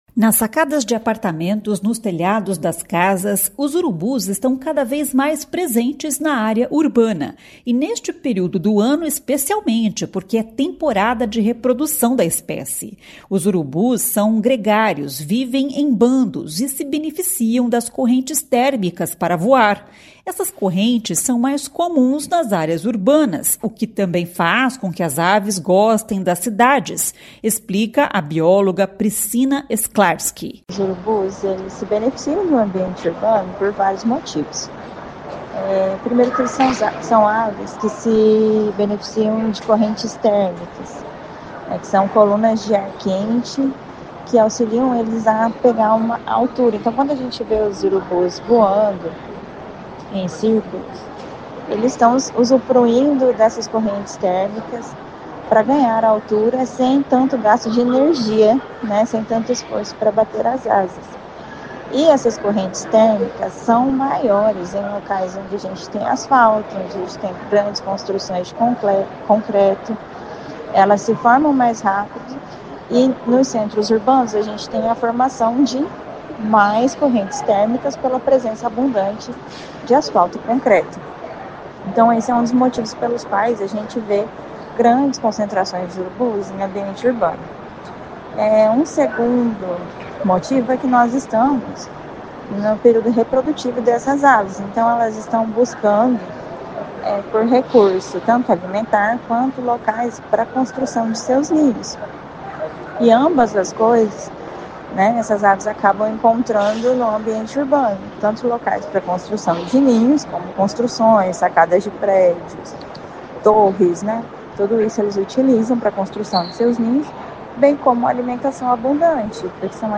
Bióloga explica que este período do ano é de acasalamento dos urubus, por isso a ave está mais presente na cidade.